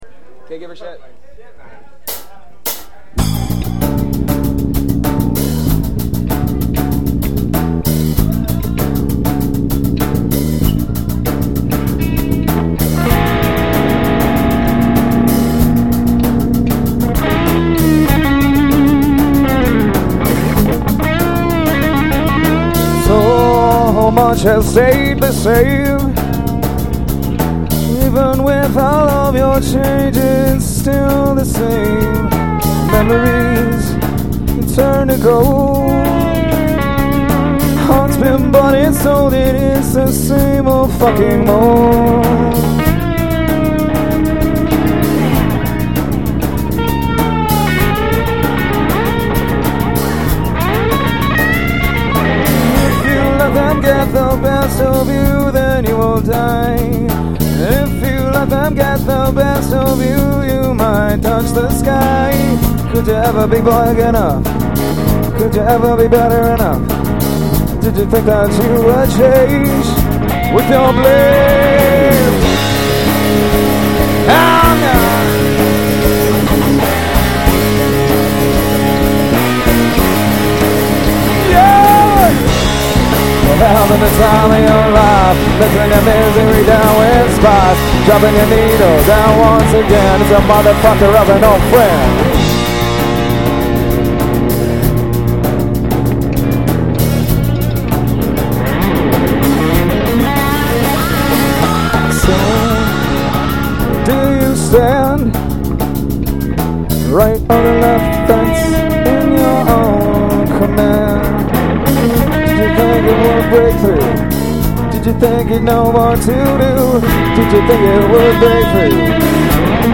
These songs are taken from a live show in Whitehorse.